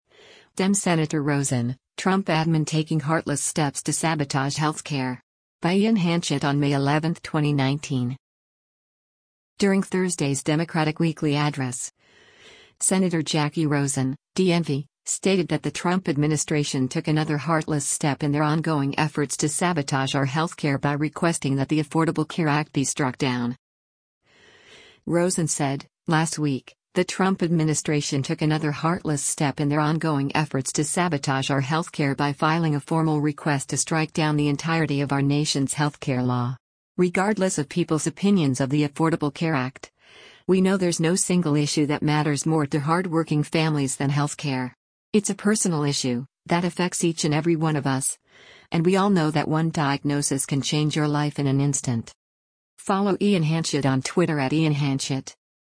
During Thursday’s Democratic Weekly Address, Senator Jacky Rosen (D-NV) stated that the Trump administration “took another heartless step in their ongoing efforts to sabotage our health care” by requesting that the Affordable Care Act be struck down.